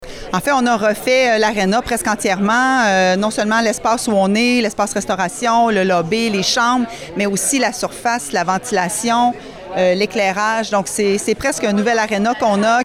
La mairesse de Nicolet, Geneviève Dubois a indiqué qu’un tel match n’aurait pas pu avoir lieu il y a seulement quelques années, soit avant les travaux de rénovation de la bâtisse.